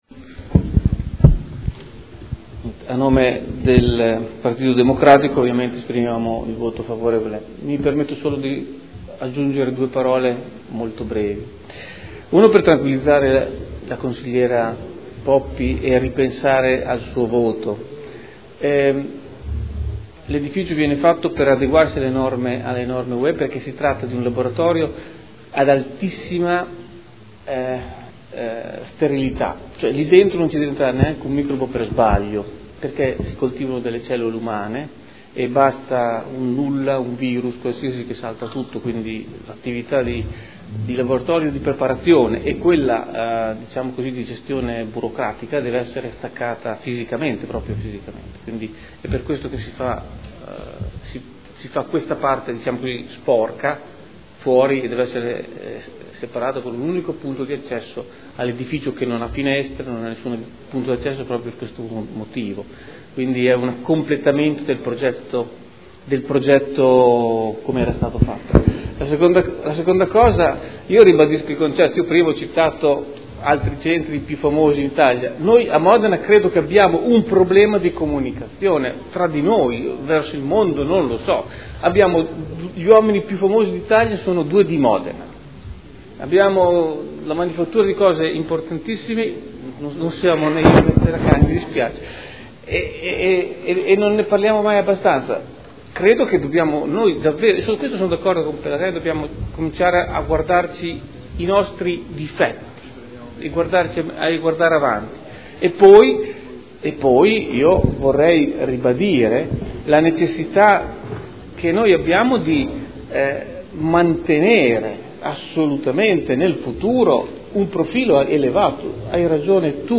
Seduta del 31 marzo. Proposta di deliberazione: Proposta di progetto - Ampliamento del Centro Medicina Rigenerativa – Via Gottardi – Z.E. 473 area 01 – Nulla osta in deroga agli strumenti urbanistici comunali – Art. 20 L.R. 15/2013. Dichiarazioni di voto